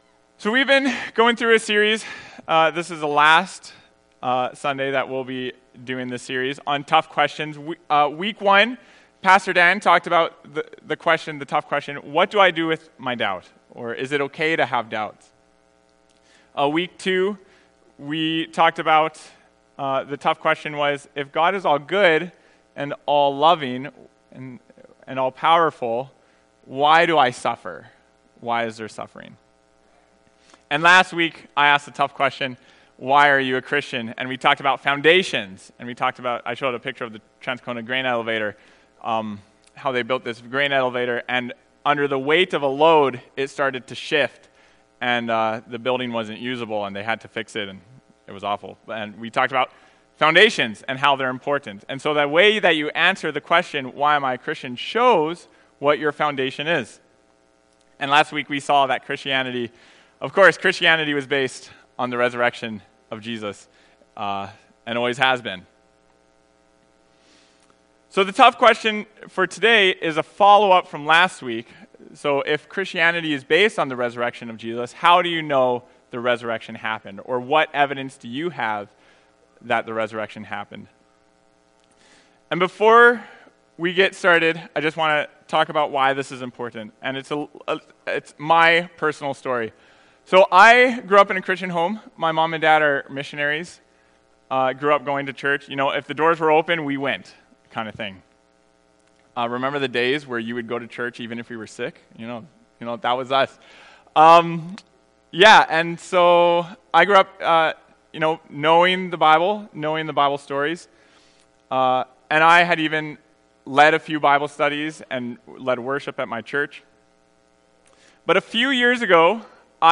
Tough Questions Service Type: Sunday Morning Preacher